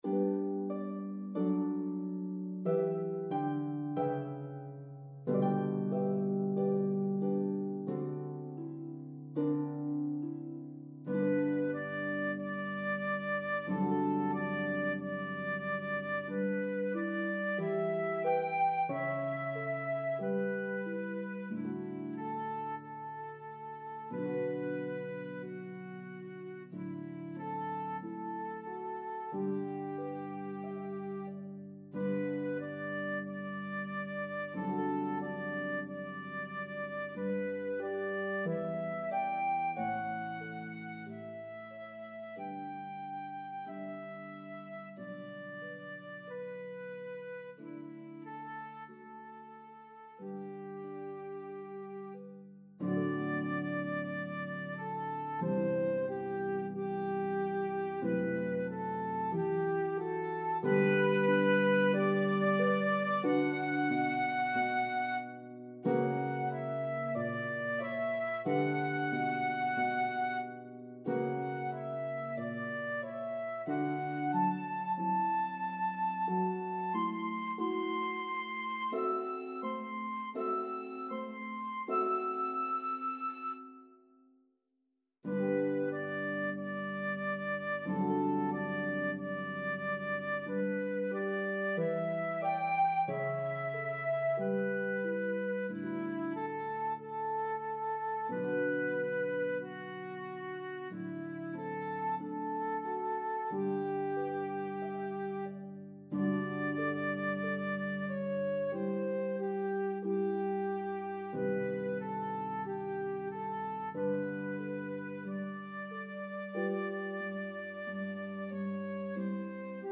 The elegant, famous melody will enchant your audiences!
Harp and Flute version